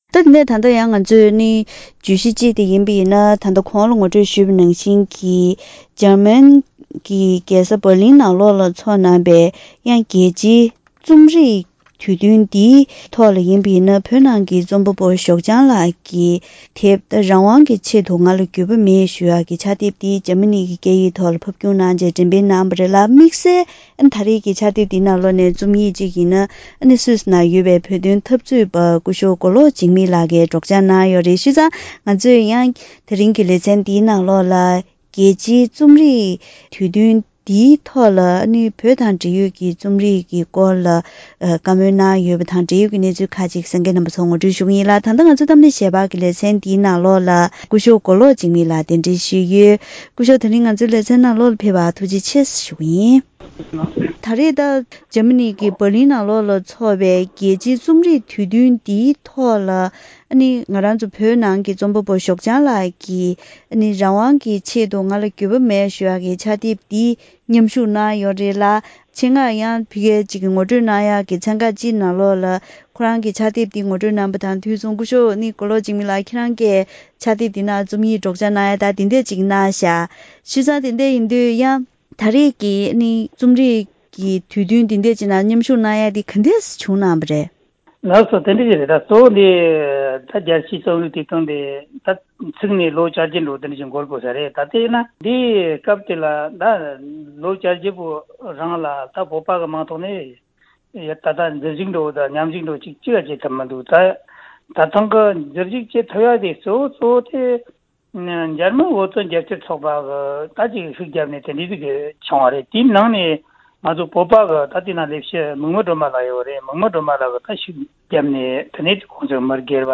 ༄༅། །ཐེངས་འདིའི་གཏམ་གླེང་ཞལ་པར་ལེ་ཚན་ནང་།